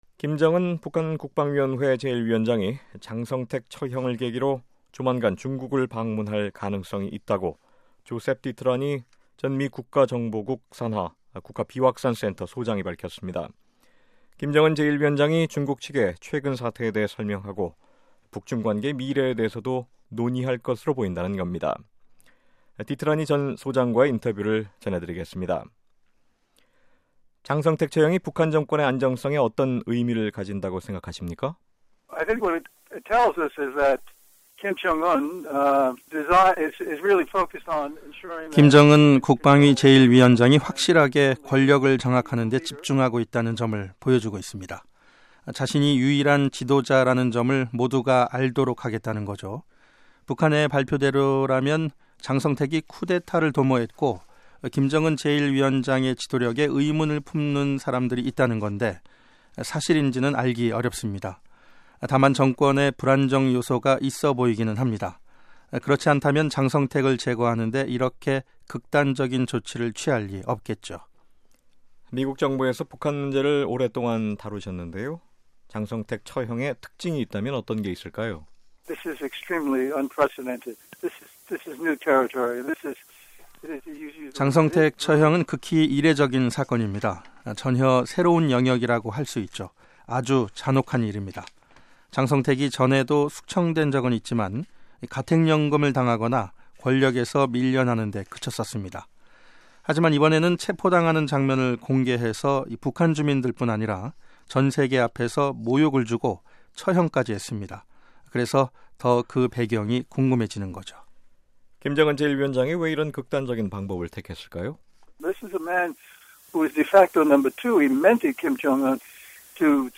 [인터뷰] 조셉 디트라니 전 미 국가비확산센터 소장 "김정은 조만간 방중 가능성"